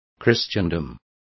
Complete with pronunciation of the translation of christendoms.